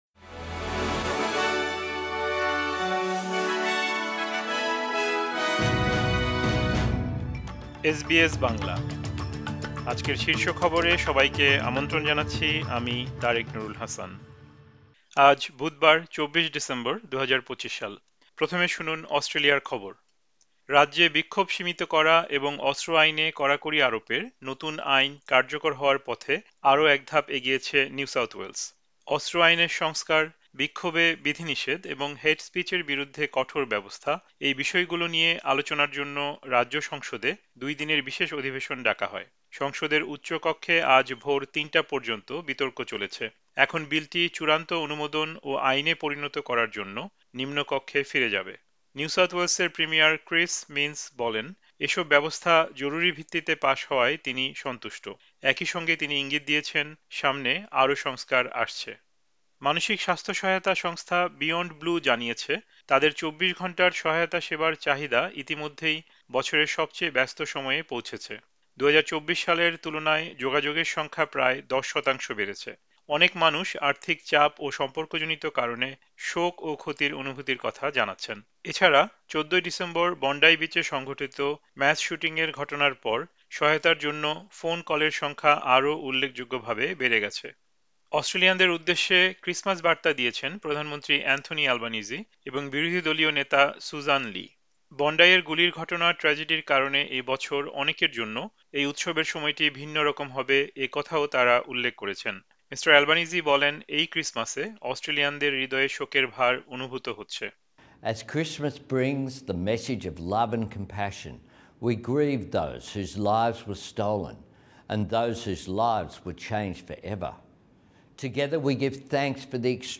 এসবিএস বাংলা শীর্ষ খবর: বিক্ষোভ সীমিত ও অস্ত্র আইনে নতুন কড়াকড়ি কার্যকর হওয়ার পথে আরও এক ধাপ এগিয়েছে নিউ সাউথ ওয়েলস